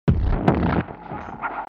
دانلود آهنگ رادیو 12 از افکت صوتی اشیاء
دانلود صدای رادیو 12 از ساعد نیوز با لینک مستقیم و کیفیت بالا
جلوه های صوتی